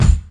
• 2000s Focused Steel Kick Drum Sound E Key 119.wav
Royality free kick sound tuned to the E note. Loudest frequency: 391Hz